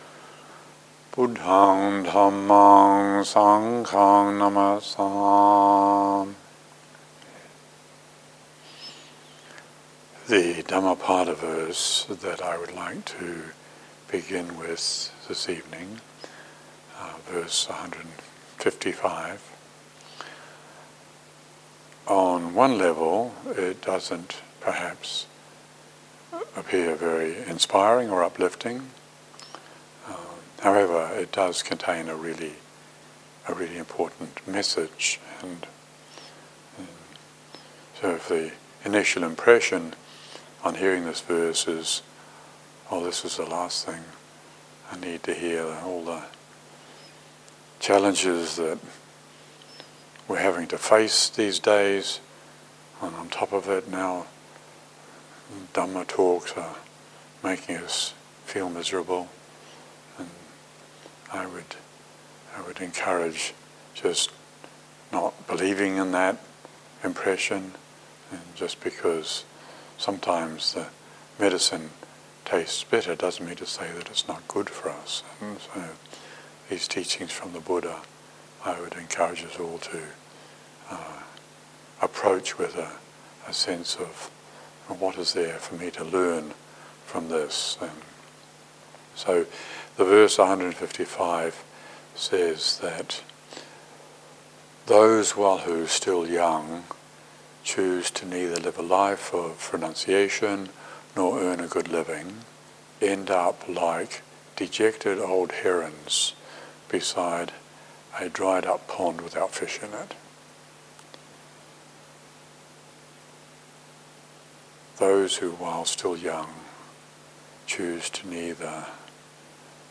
Dhamma talks